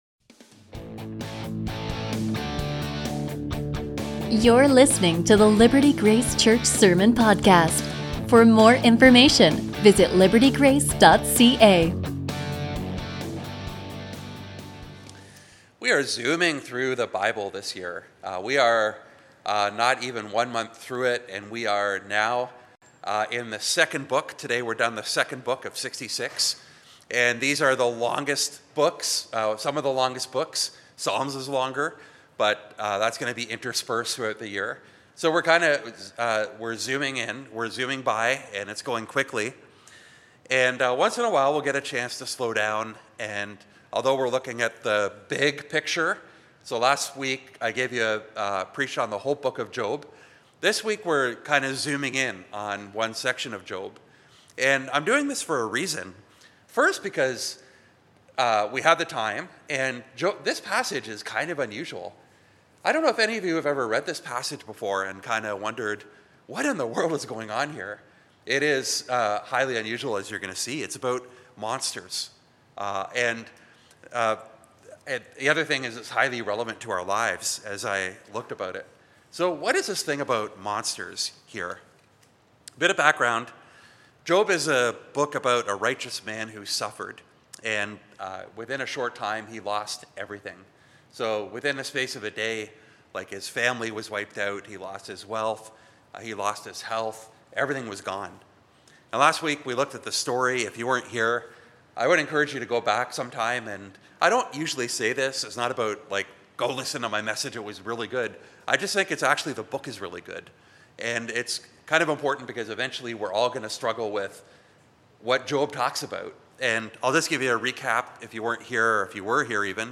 A sermon from Job 40:15-41:34